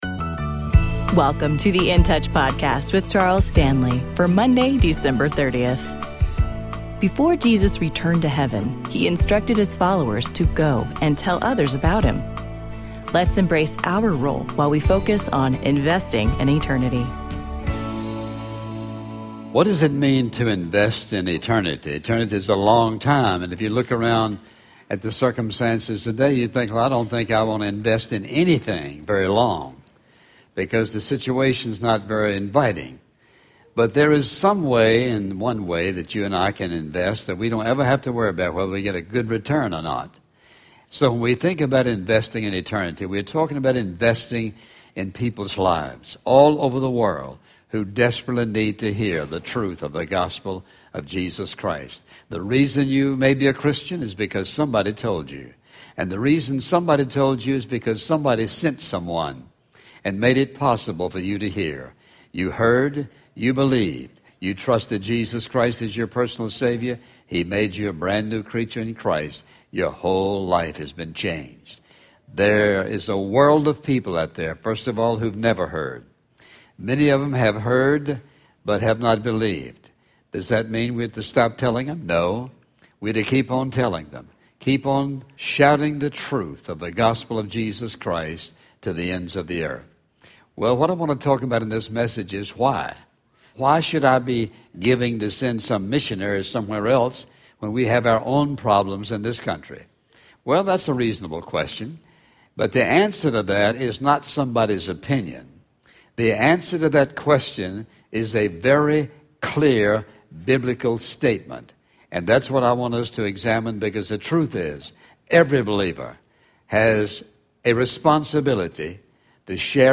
Dr. Charles Stanley and In Touch Ministries’ daily radio program.